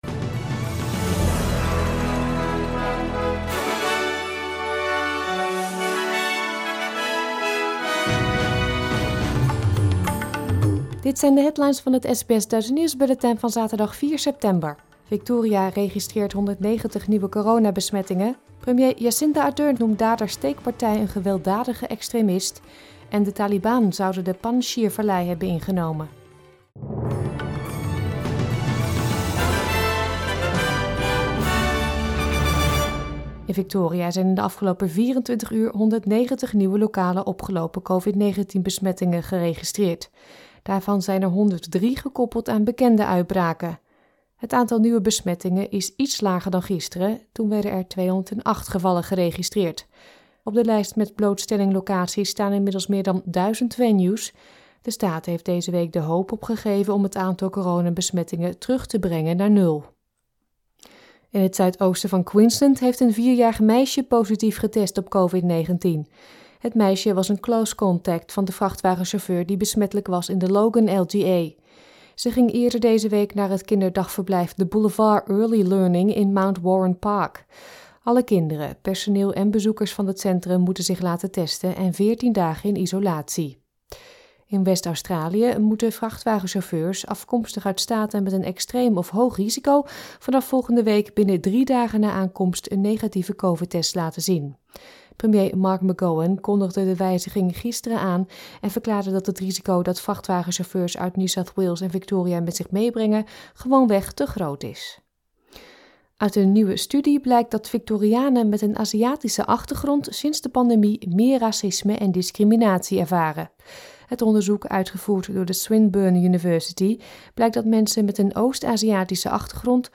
Nederlands/Australisch SBS Dutch nieuwsbulletin van zaterdag 4 september 2021